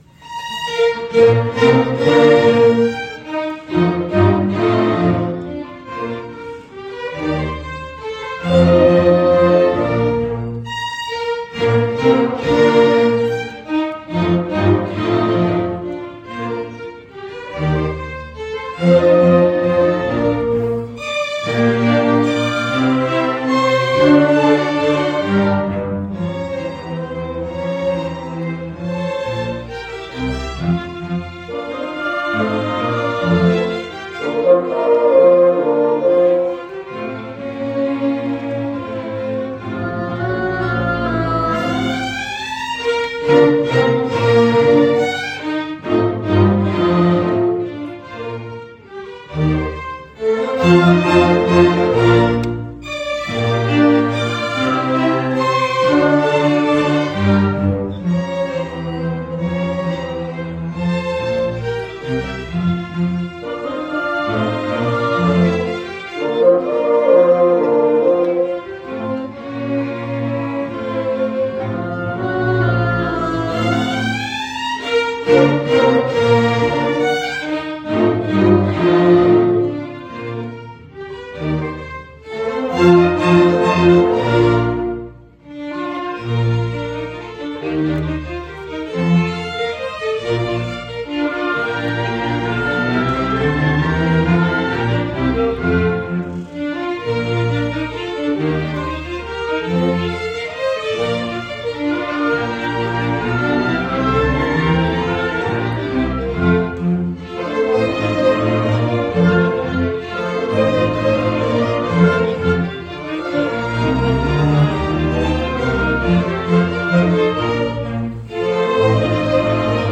Concerts – eSSO :: endless Summer Symphony Orchestra